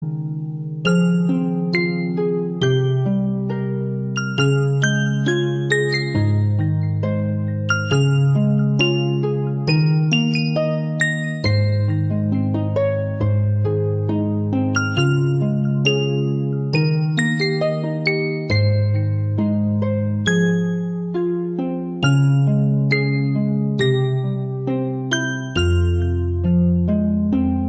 BGM Instrumental Ringtone